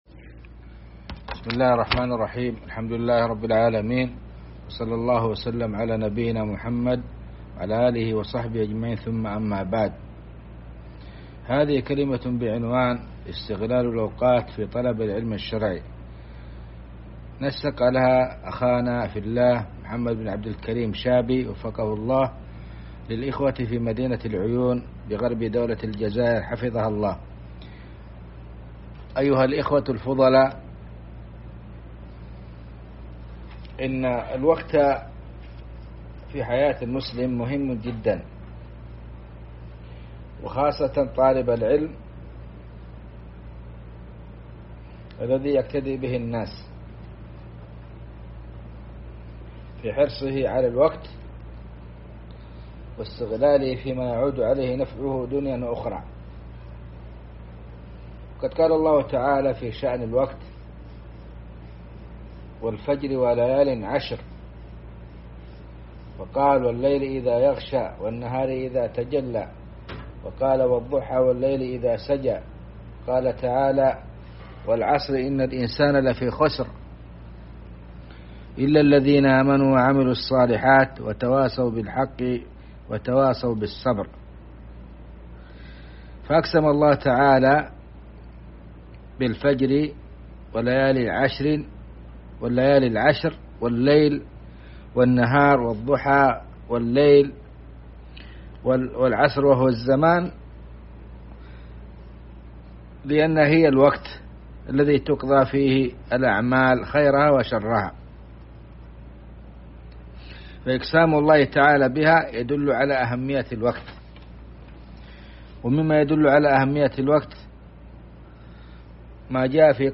محاضرة